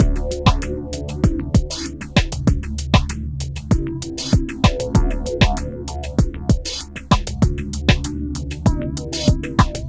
Downtempo 17.wav